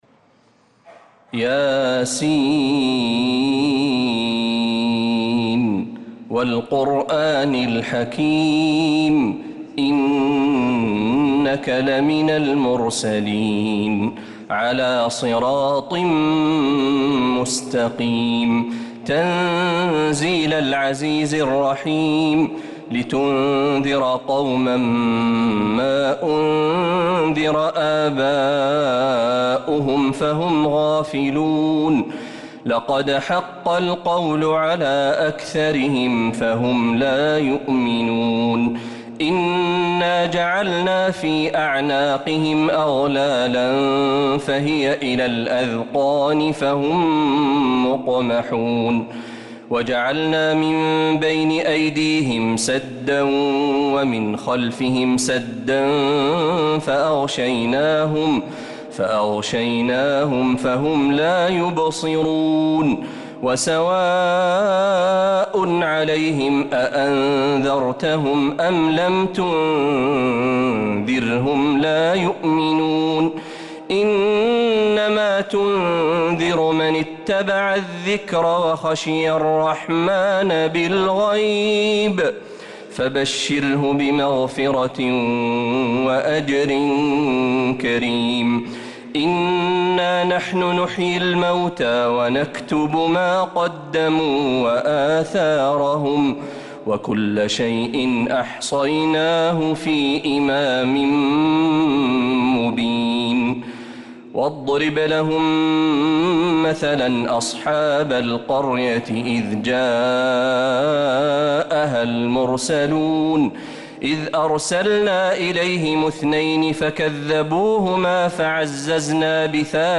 سورة يس كاملة للشيخ محمد برهجي مجمعة من تراويح و فروض الحرم النبوي > السور المكتملة للشيخ محمد برهجي من الحرم النبوي 🕌 > السور المكتملة 🕌 > المزيد - تلاوات الحرمين